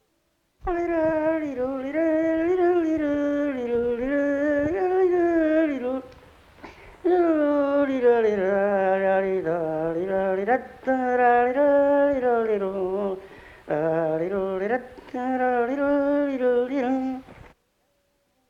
Genre : expression vocale
Effectif : 1
Type de voix : voix d'homme
Production du son : effet de voix
Danse : mazurka